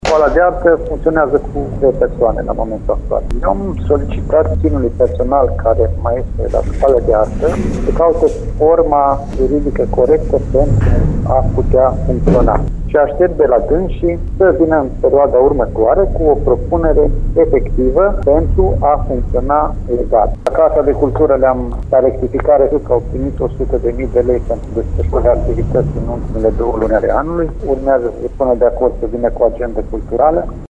Cele două instituții vor fi însă reorganizate, pentru că în momentul de față nu mai au personal suficient și nici activitate, spune primarul interimar al Lugojului Bogdan Blidariu.